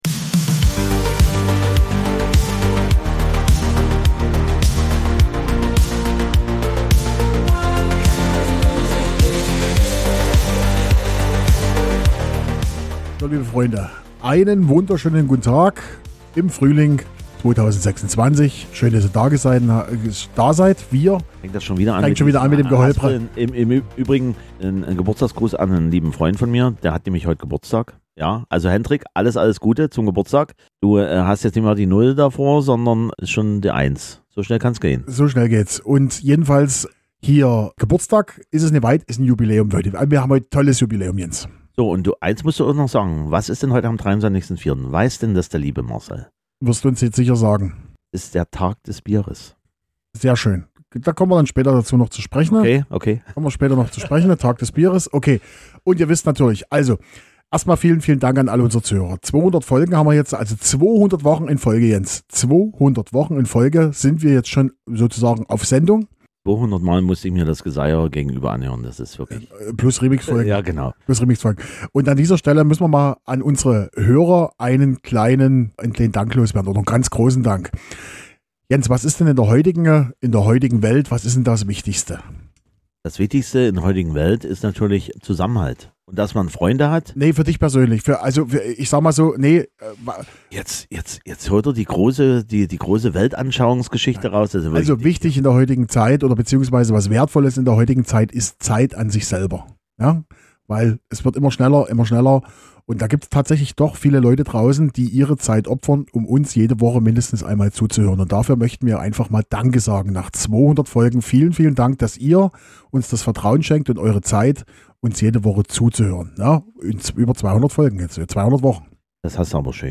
Links Folge 39 - Oktober 1978 Folge 70 - Januar 1978 Folge 165 - September 1978 KI-Song zu dieser Folge Credits Podcastintro/-outro by Suno Hosted on Acast.